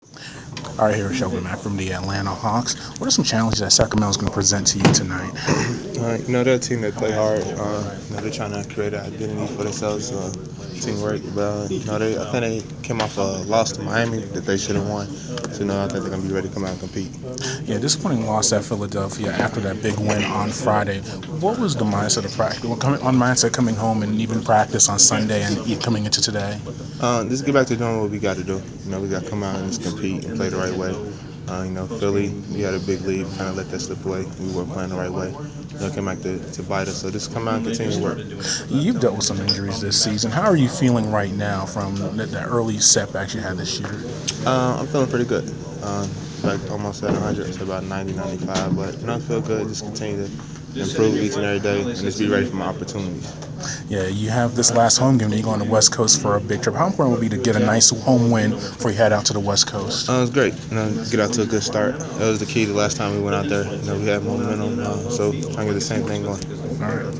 Inside the Inquirer: Pregame interview with Atlanta Hawk Shelvin Mack (3/9/15)
shelvin-mack-hawks-sacramento-pre-game.wav